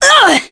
Demia-Vox_Damage_kr_01.wav